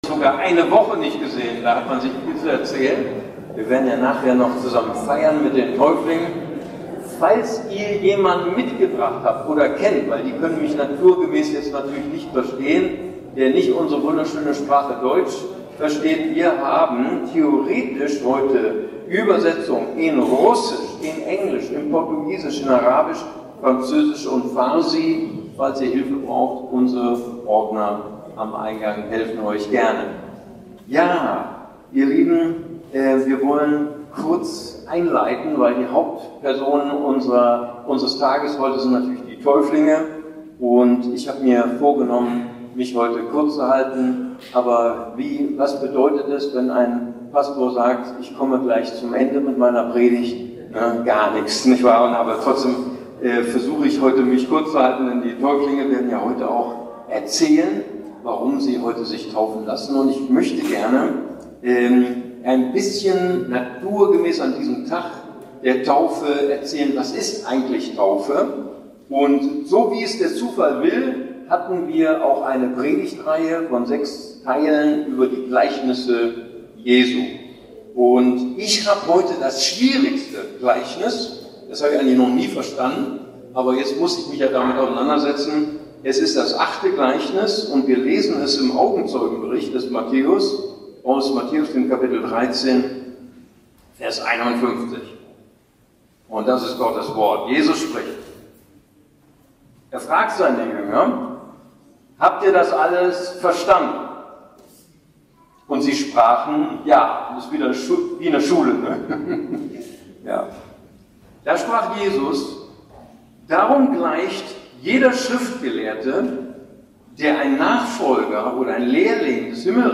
ICB Predigtreihe: Gleichnisse Jesu Teil 8: Altes und Neues